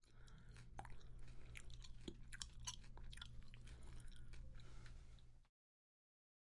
食用；耐嚼；接近
描述：令人不安的咀嚼食物的密切记录。
Tag: 进食 咀嚼